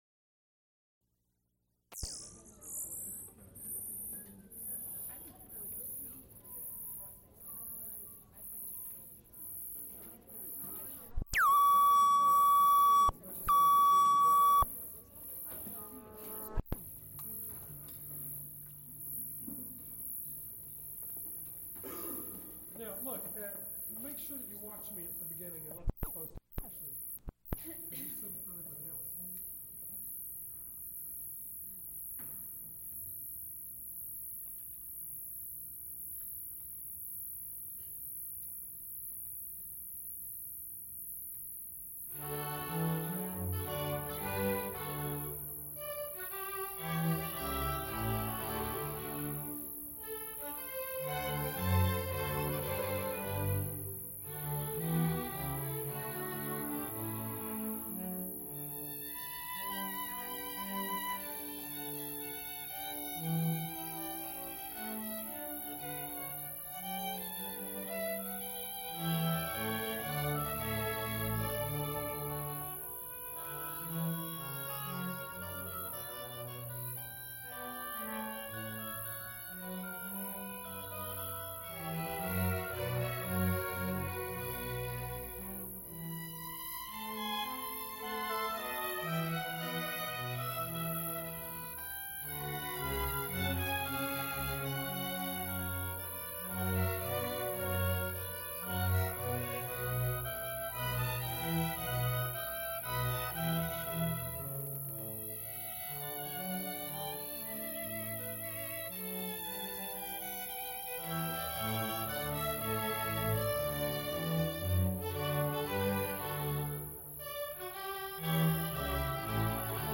Multiple false starts on each tape.
Recorded live January 9, 1976, Heinz Chapel, University of Pittsburgh.
Extent 3 audiotape reels : analog, half track, stereo, 7 1/2 ips ; 7 in.
Choruses, Sacred (Mixed voices) with orchestra
Gregorian chants